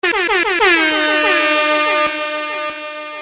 djhorn.mp3